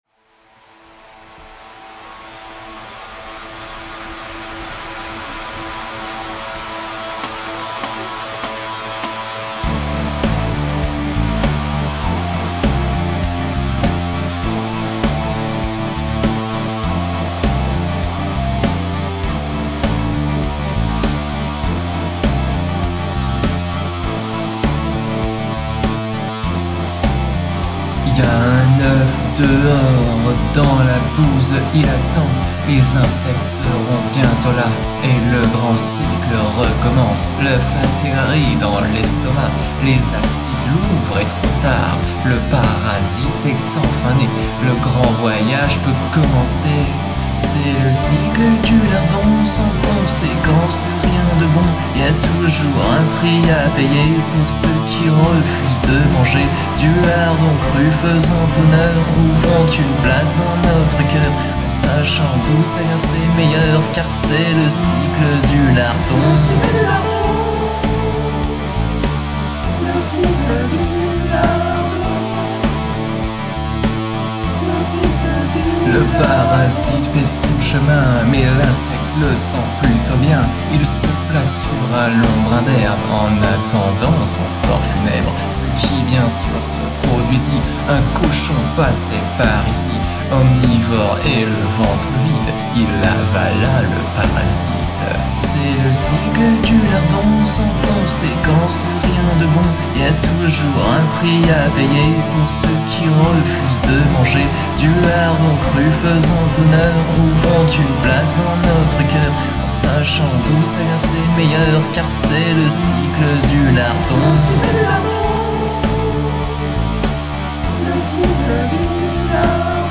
Chanson de prevention sur le lardon cru : Ici